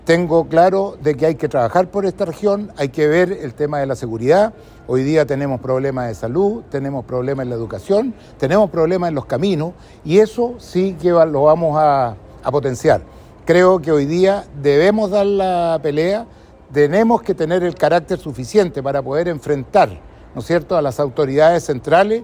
En conversación con Radio Bío Bío, Manoli dijo que su trabajo se enfocará en la seguridad, salud y educación.